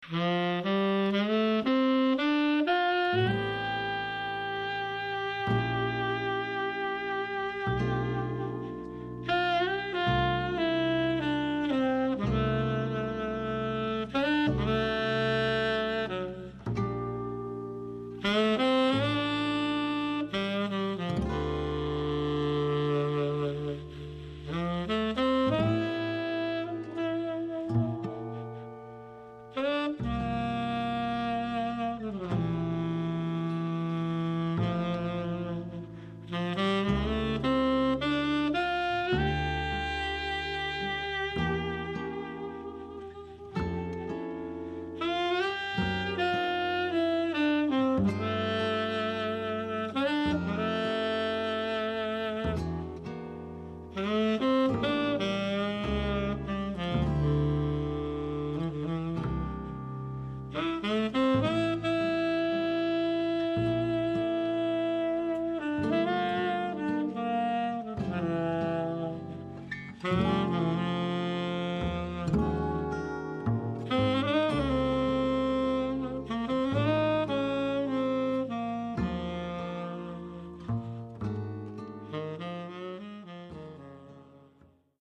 Sax, Guitar & Bass